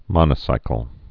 (mŏnə-sīkəl)